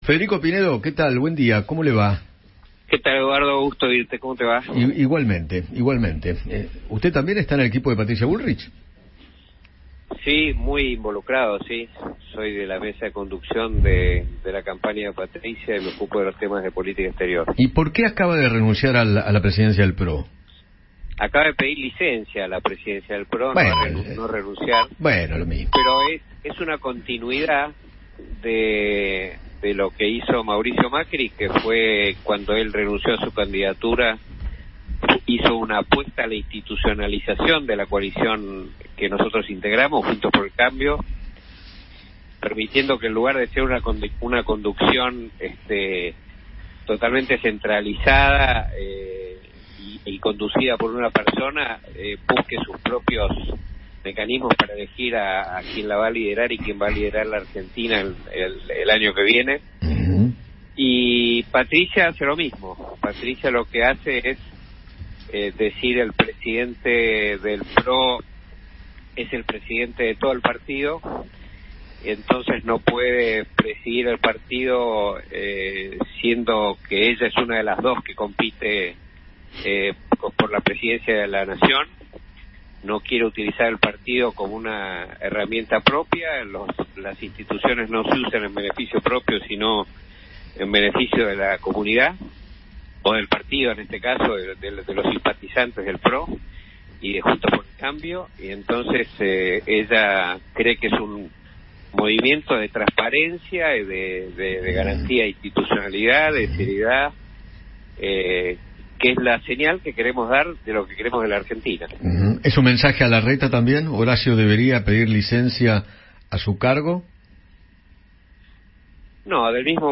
Federico Pinedo, parte del equipo de campaña de Patricia Bullrich, dialogó con Eduardo Feinmann sobre la decisión de la presidenta del PRO para enfocarse en la campaña electoral.